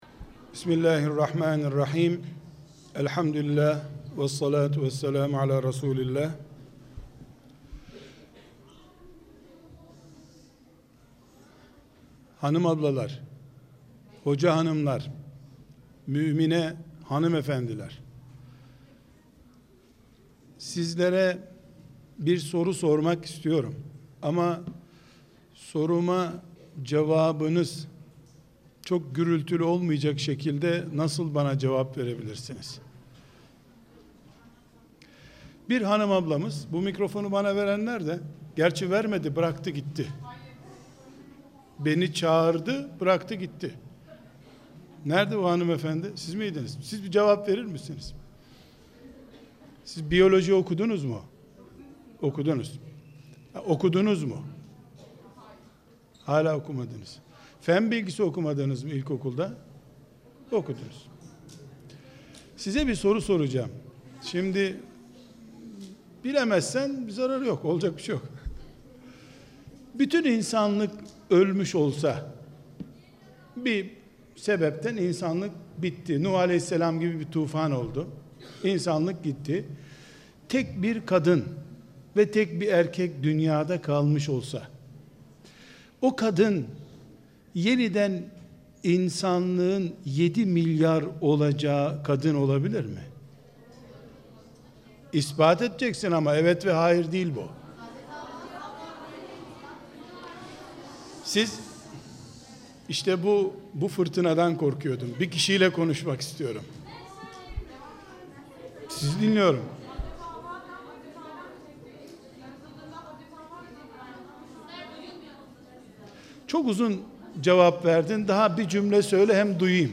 kazim-karabekir-a.i.h.l-siz-asiyesiniz-konferansi.mp3